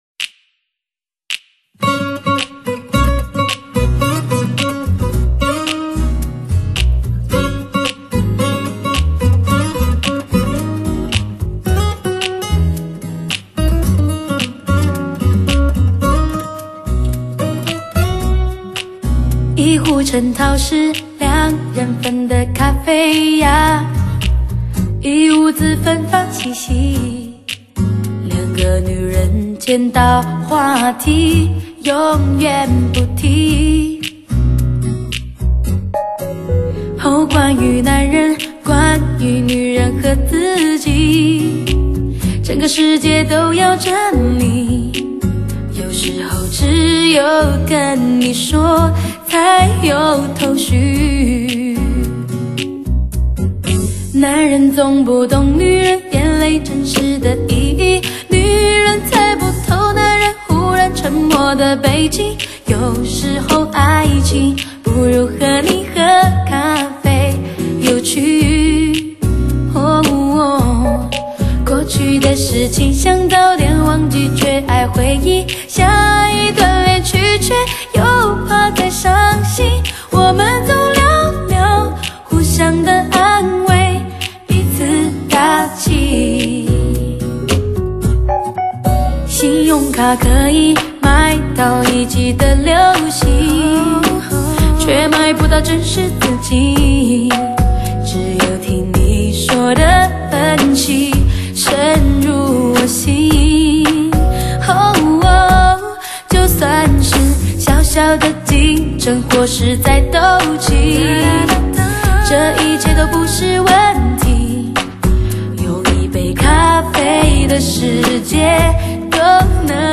伴奏录制 人声录制 人声效果混音 合成混缩 达至空前卓越的水准